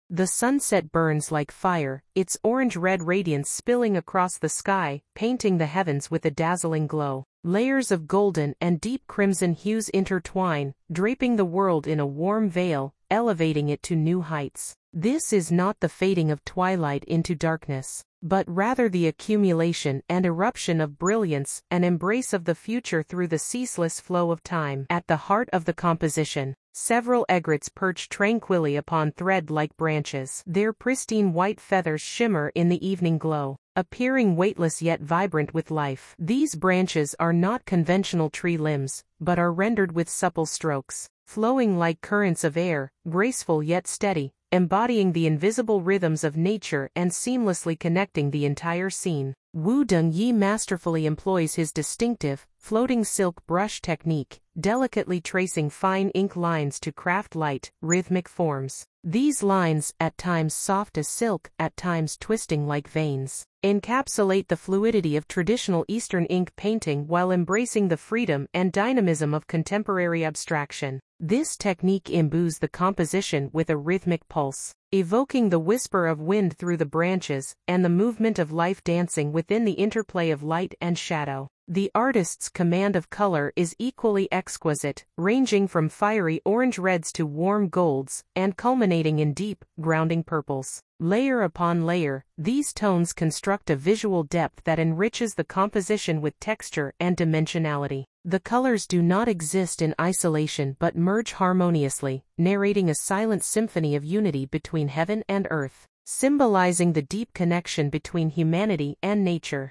English audio guide